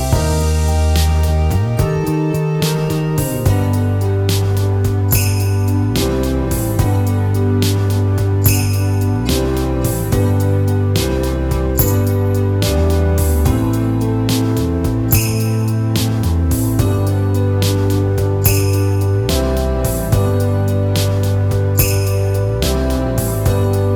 One Semitone Down Rock 4:19 Buy £1.50